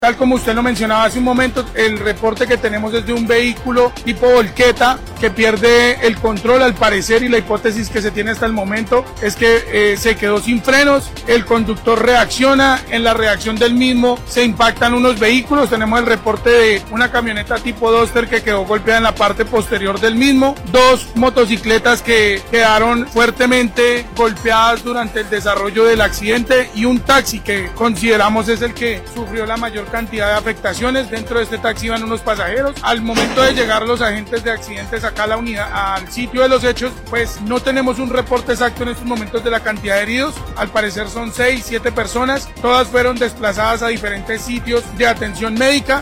Jaime Becerra, secretario de tránsito y movilidad de la ciudad de Florencia, explicó que, al lugar llegaron los expertos en accidentes y tras evaluar la escena del siniestro, la principal hipótesis sería una falla mecánica que afecto el sistema de frenos.